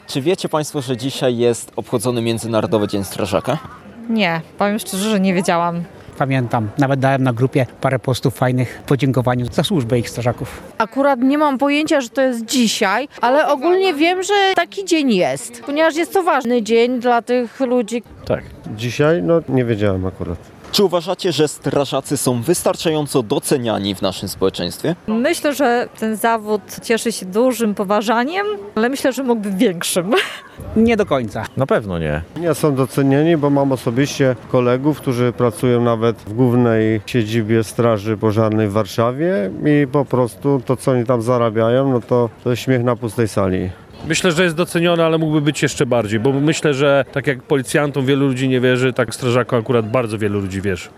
Międzynarodowy Dzień Strażaka (sonda)